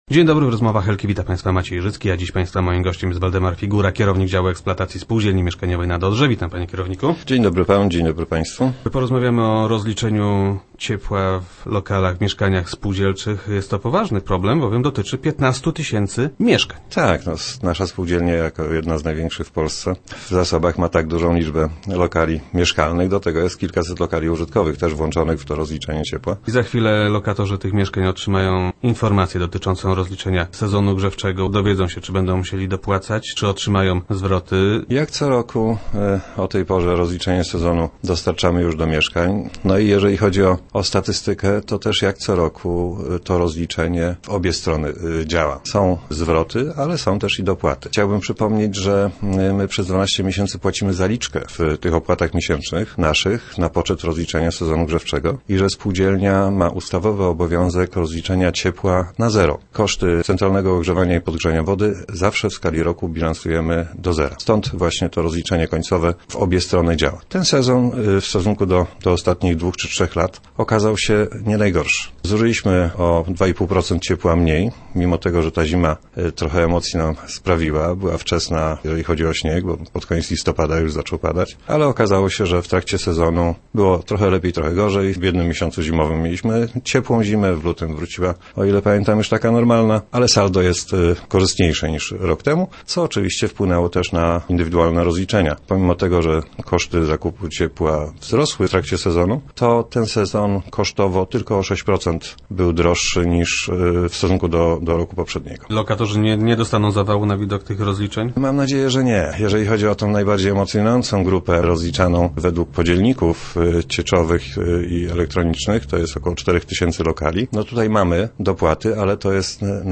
Gość Rozmów Elki zapewnia, że rachunki nie powinny szczególnie przerazić lokatorów.